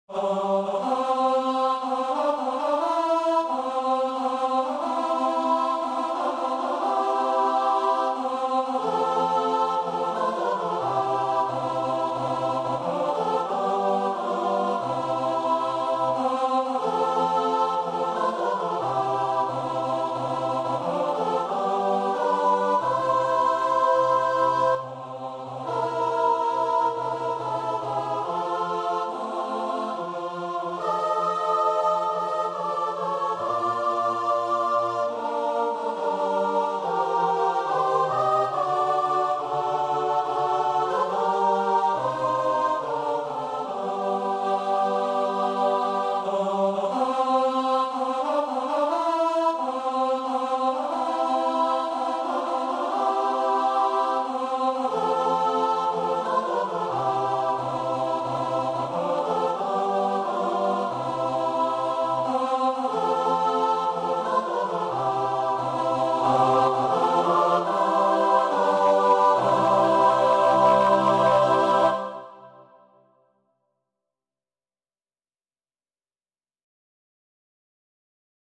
SABar (3 voix mixtes) ; Partition complète.
Pièce chorale. Strophique.
Tonalité : do majeur